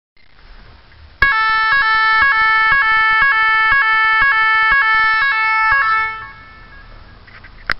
Serie: SIRENAS ELECTRÓNICAS
5 Sonidos independientes seleccionables
Gran rendimiento acústico - 110DB
Tono_1